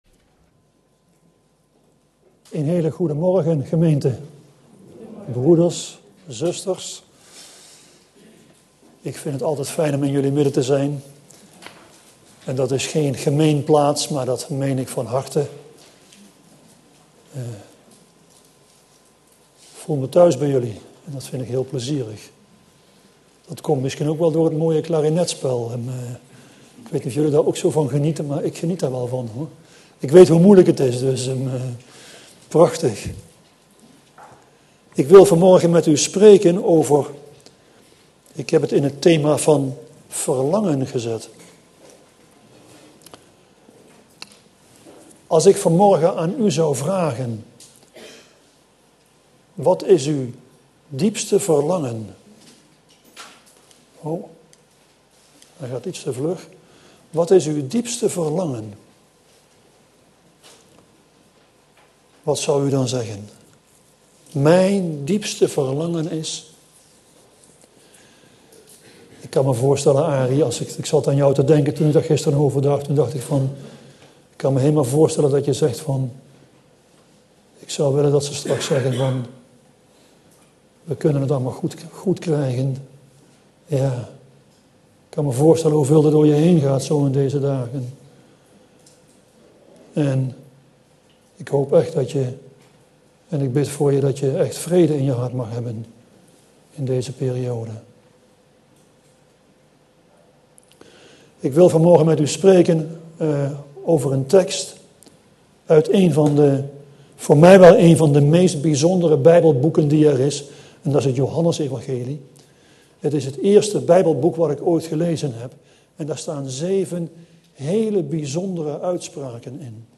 In de preek aangehaalde bijbelteksten (Statenvertaling)Johannes 8:1212 Jezus dan sprak wederom tot henlieden, zeggende: Ik ben het licht der wereld; die Mij volgt, zal in de duisternis niet wandelen, maar zal het licht des levens hebben.